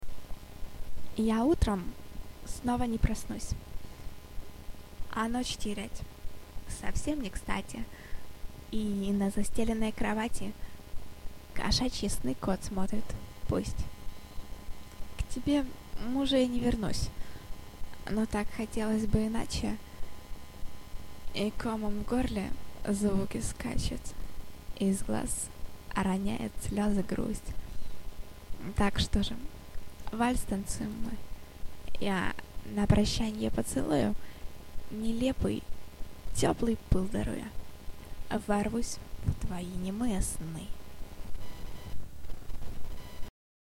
мне оч нравитса твой голос и как ты читаешь сваи стихи friends